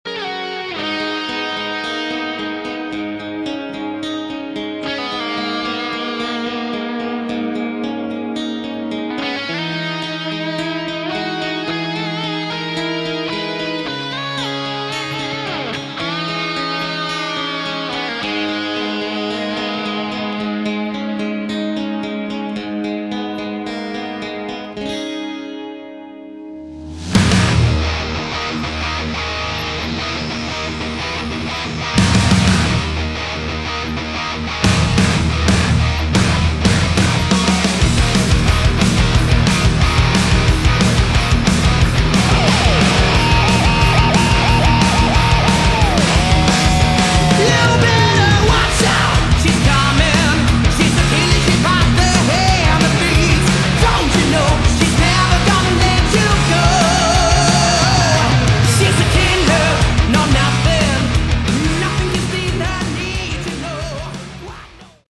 Category: Melodic Metal
This is 80s METAL.